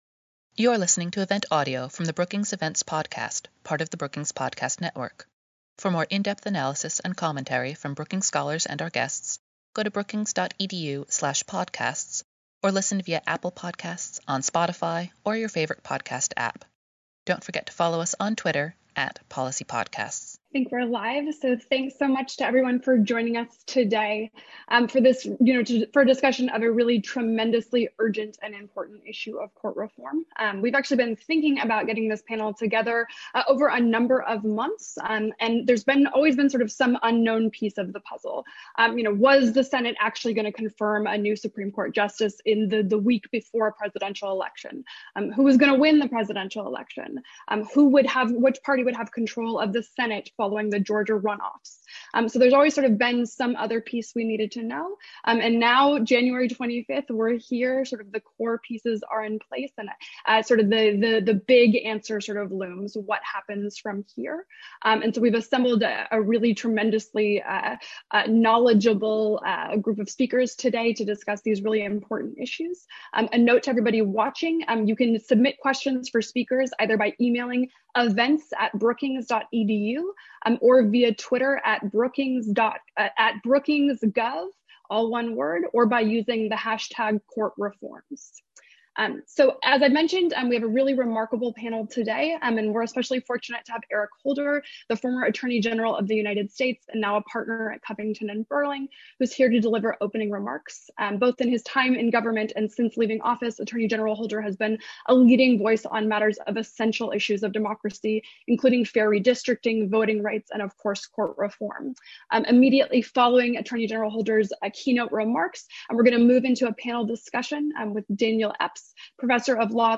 On January 25, Brookings will host an event to discuss existing judicial reform proposals, the political and procedural landscape in Congress, and the state of public debate.